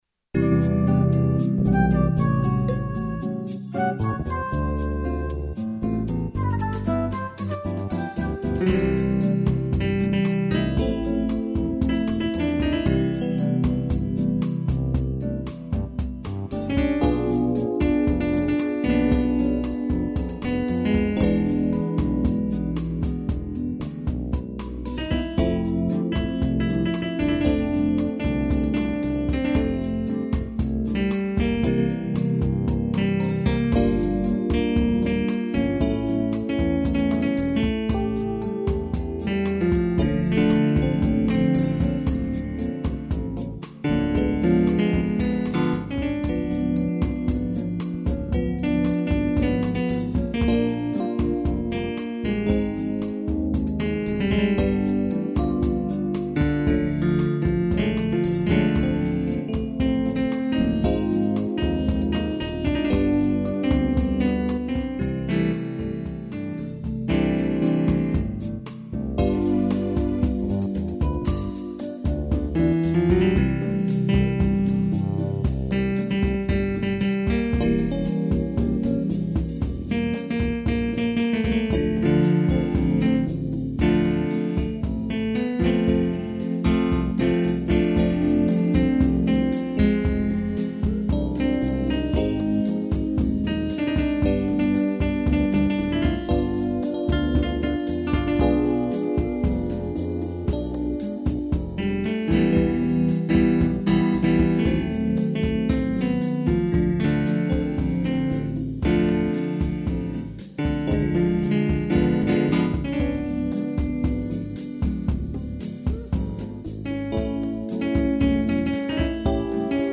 (instrumental) IV